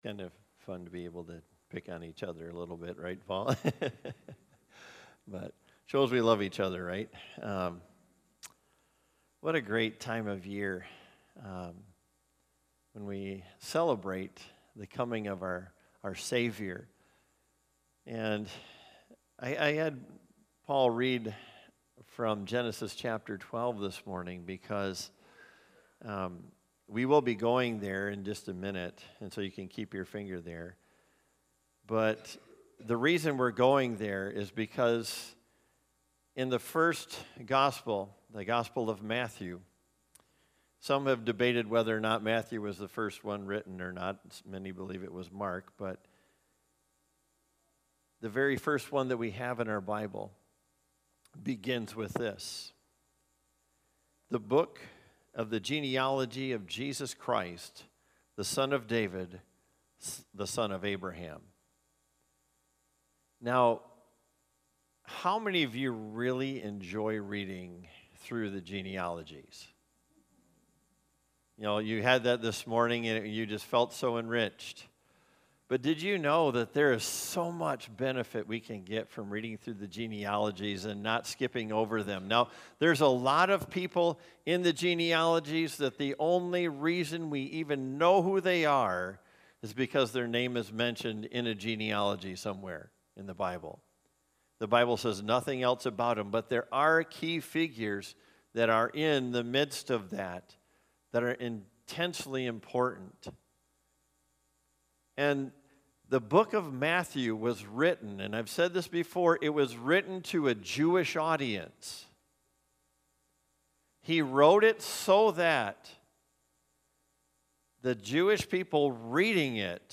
2025 Christmas Sermon Series - First Baptist Church